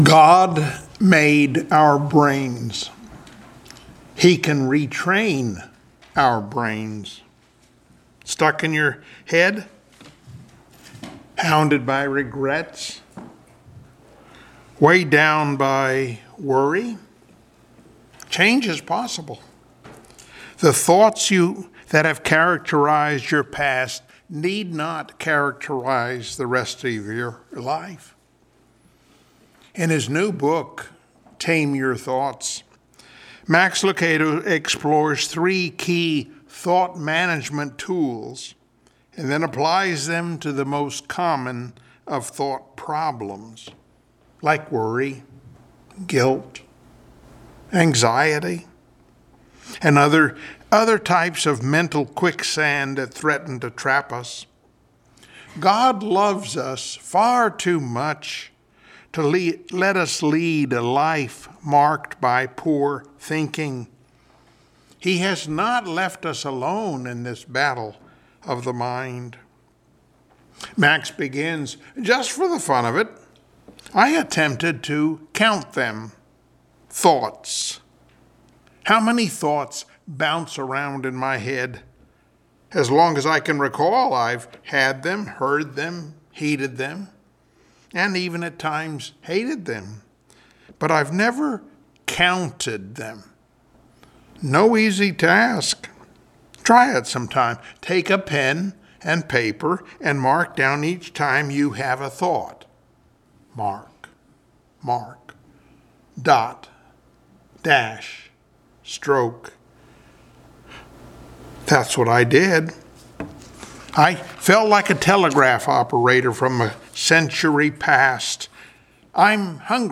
Service Type: Sunday Morning Worship Topics: Conformed VS Transformed , Play-Doh and Catapillars , Tame your thoughts , The Helet of Salvation « “God’s Job